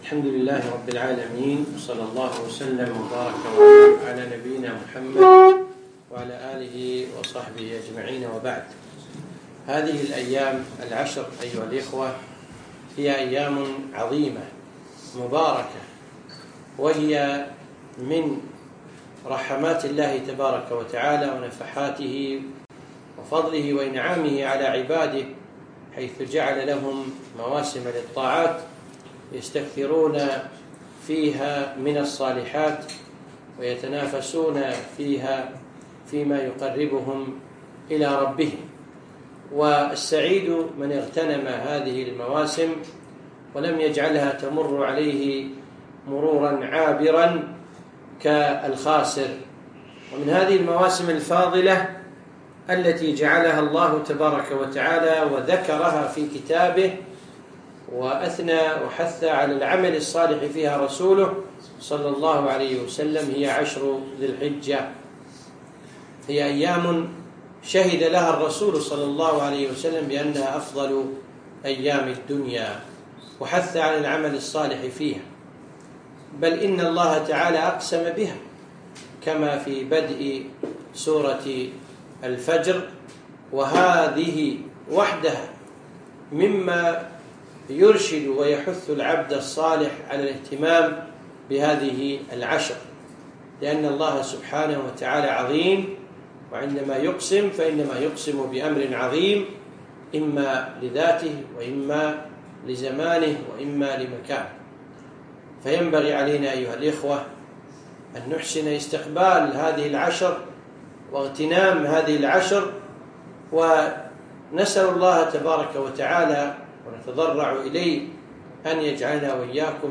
محاضرة - اغتنم العشر من ذي الحجة